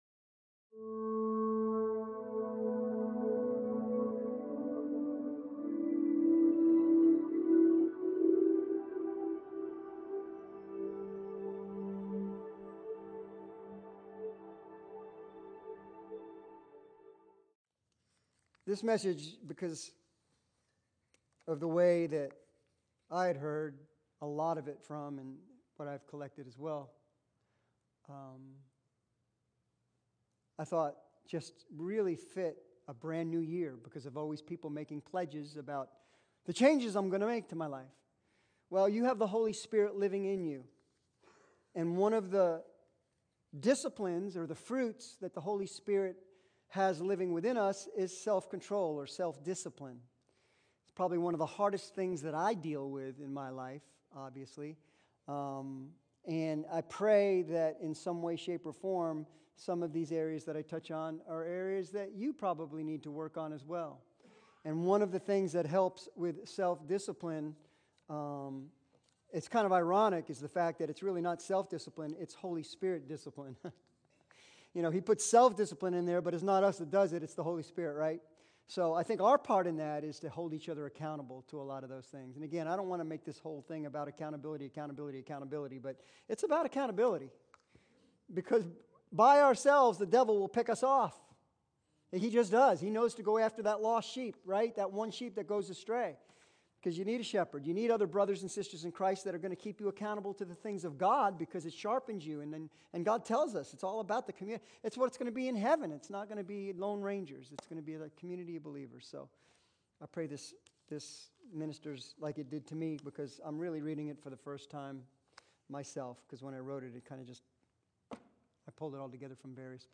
2023 Sermons